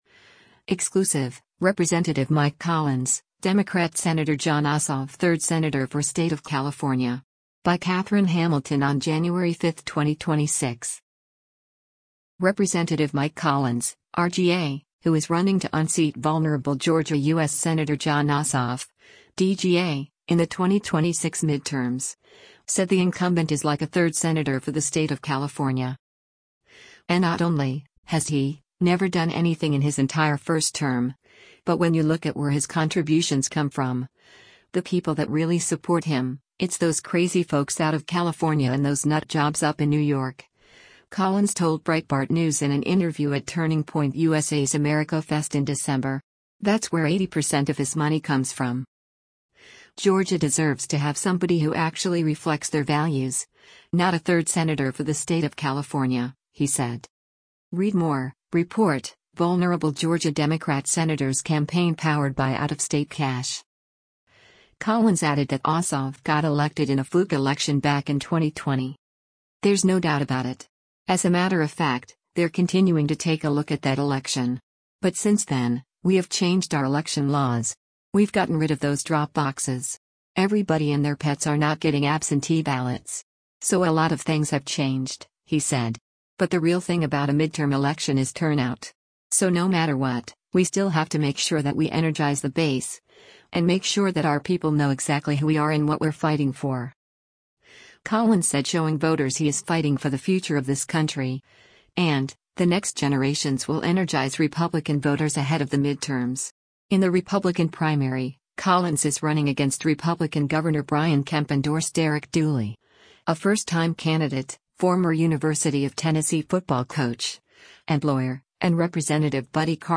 “[N]ot only … [has he] never done anything in his entire first term, but when you look at where his contributions come from, the people that really support him, it’s those crazy folks out of California and those nut jobs up in New York,” Collins told Breitbart News in an interview at Turning Point USA’s AmericaFest in December.